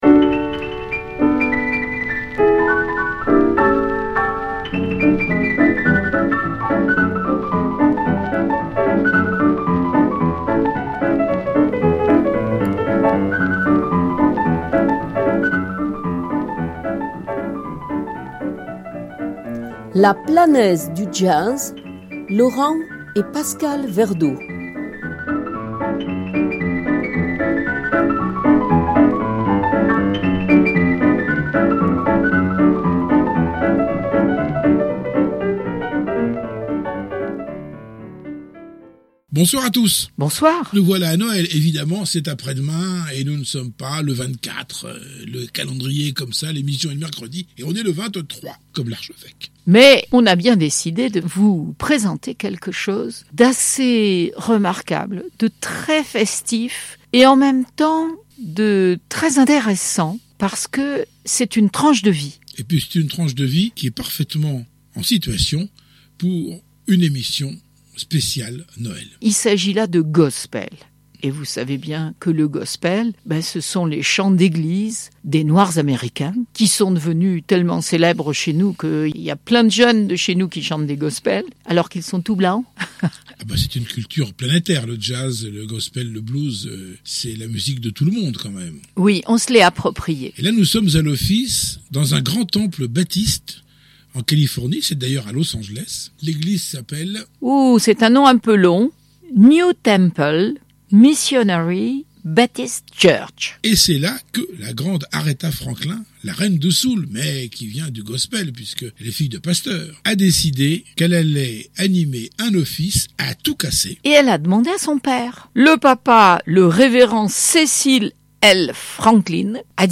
Emission sur le Jazz